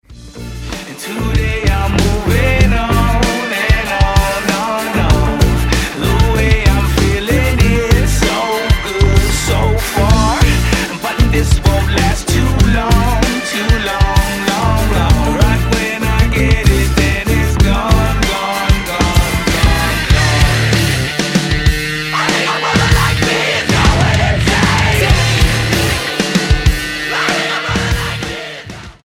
STYLE: Hard Music
is distorted punk metal
then deliciously switches to a reggae riddim